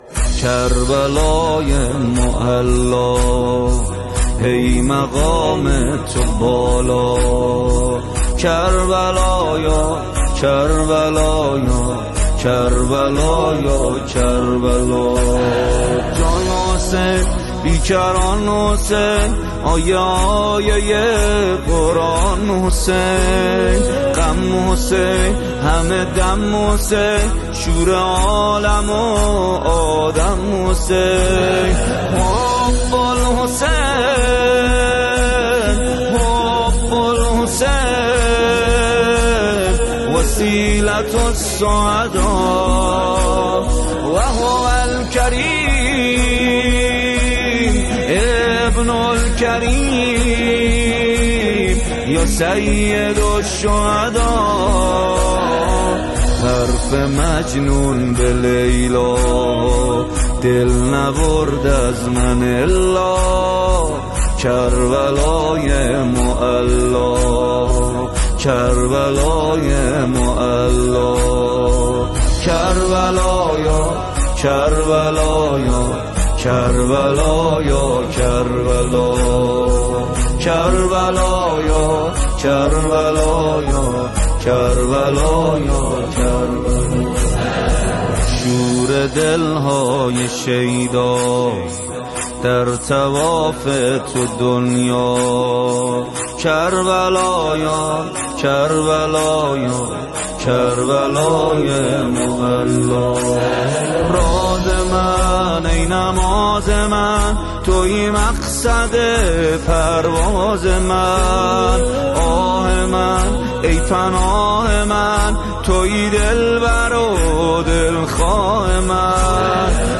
نوای دلنشین و زیبای